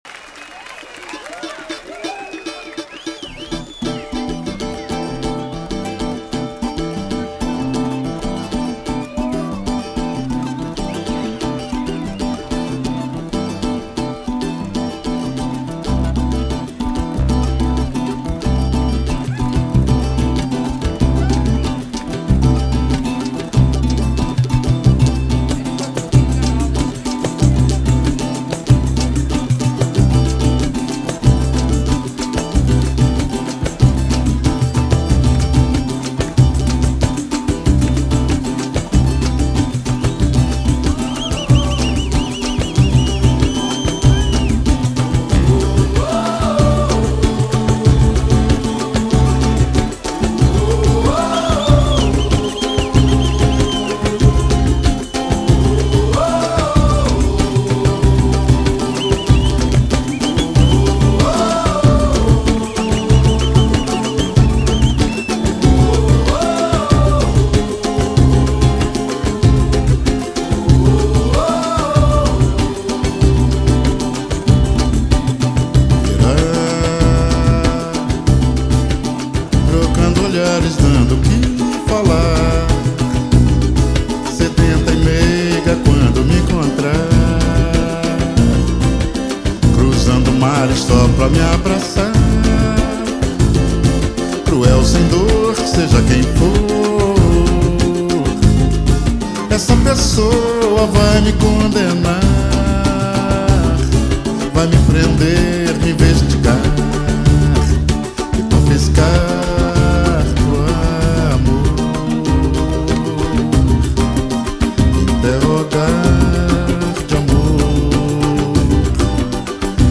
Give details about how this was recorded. Ao Vivo